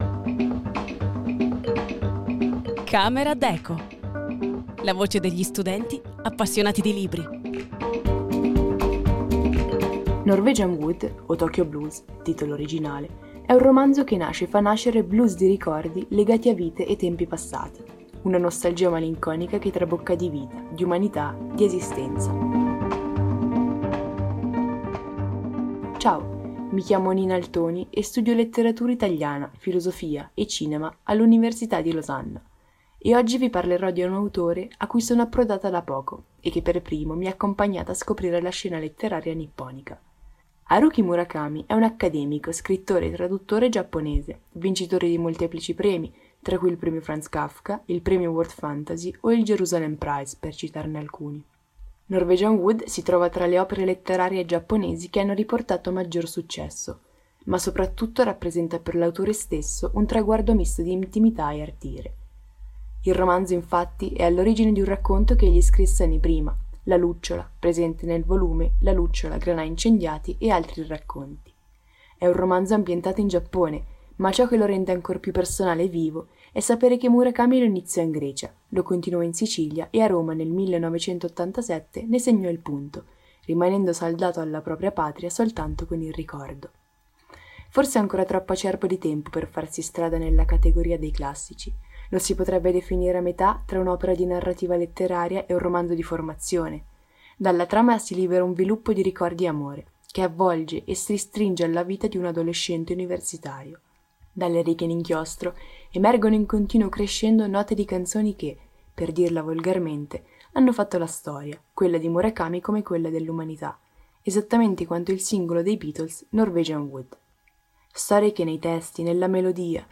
La voce degli studenti appassionati di libri